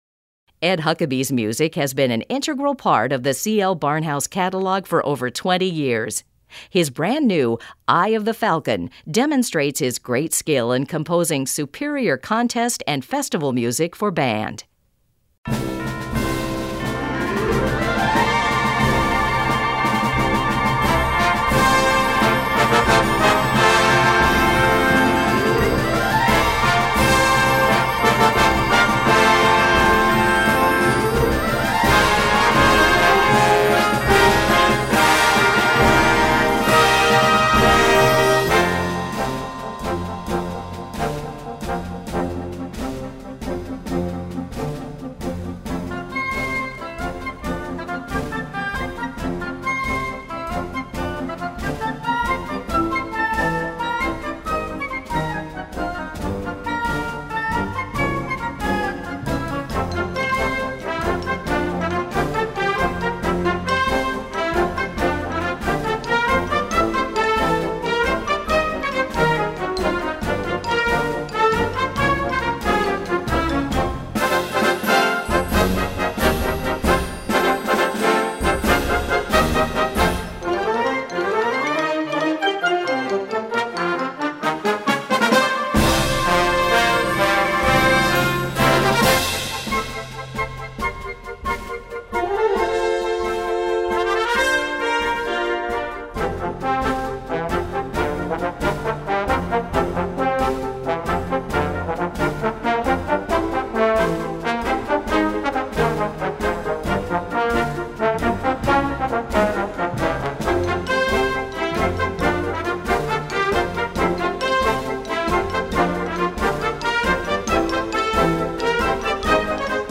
Gattung: Konzertstück
Besetzung: Blasorchester